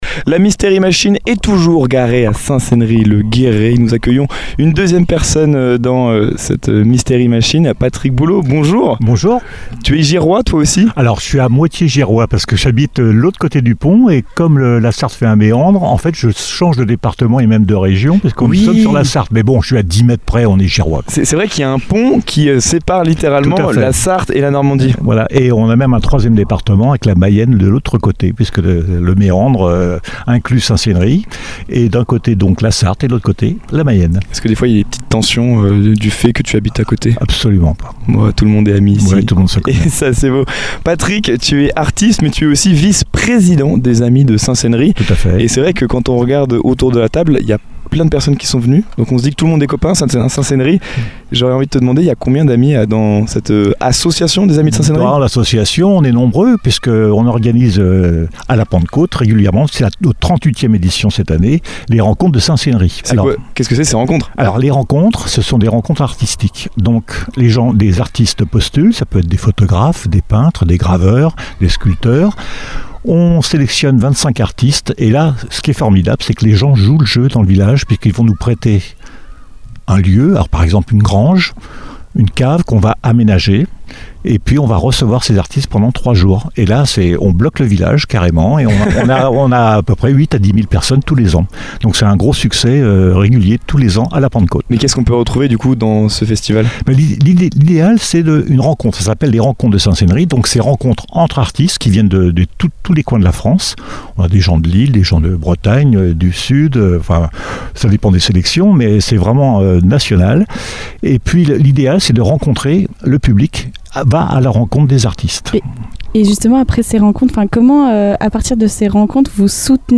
Il évoque les actions menées, les événements organisés et la volonté de transmettre l’histoire et l’âme de Saint-Céneri aux visiteurs comme aux habitants. Une interview passionnée et authentique qui met en lumière le rôle essentiel du tissu associatif dans la protection et la mise en valeur du patrimoine local.